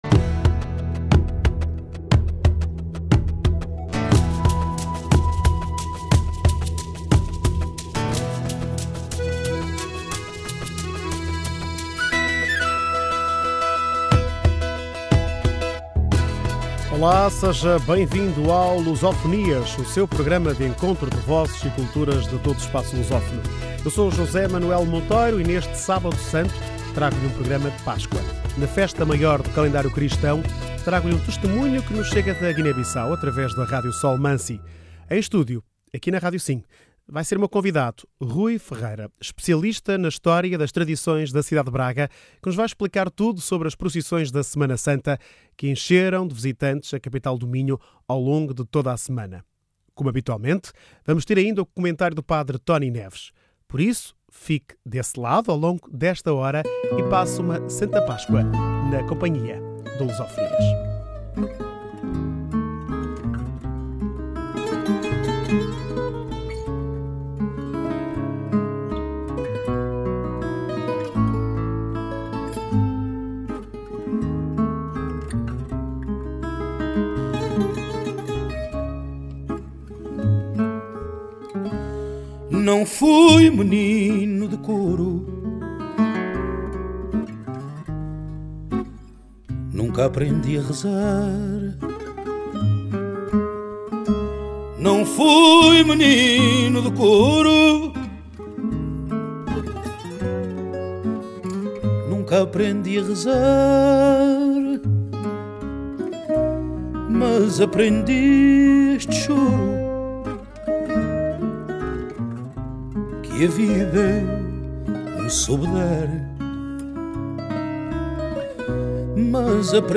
Neste Sábado Santo o Luso Fonias é um programa de Páscoa. Na festa maior do calendário cristão, chega-nos um testemunho da Guiné-Bissau, através da Rádio Sol Mansi.